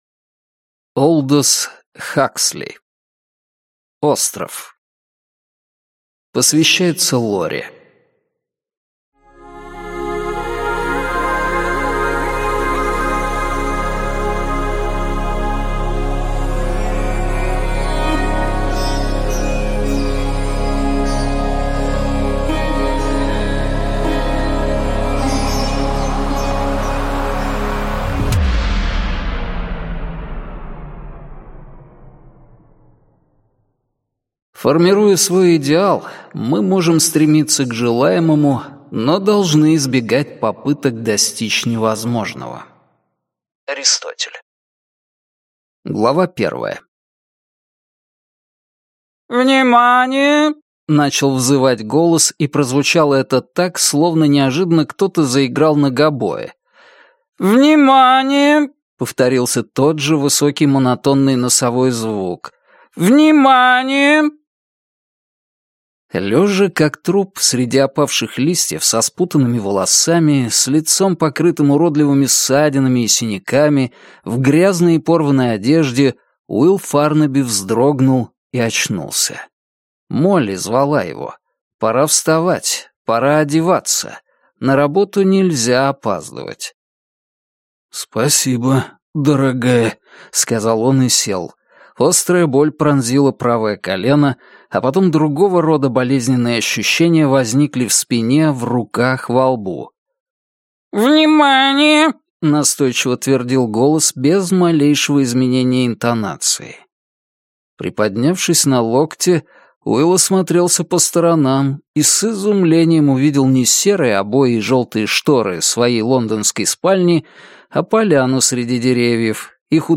Аудиокнига Остров | Библиотека аудиокниг